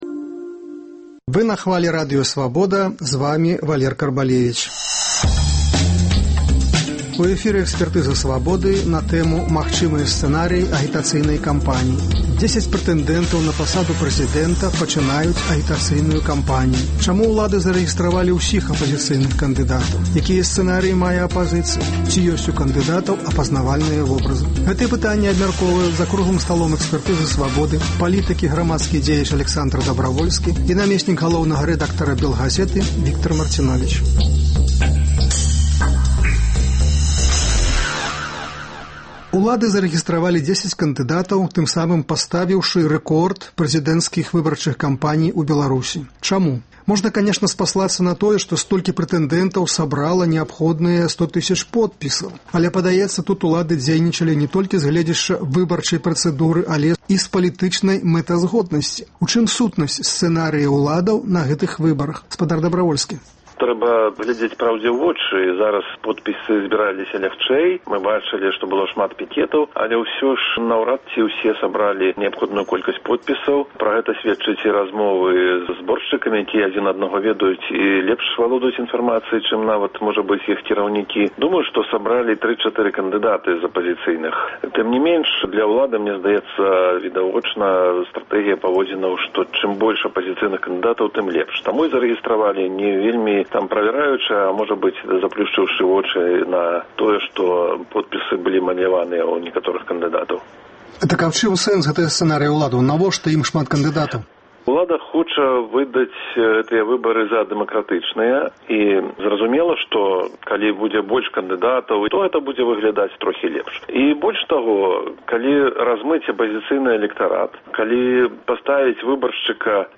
Гэтыя пытаньні абмяркоўваюць за круглым сталом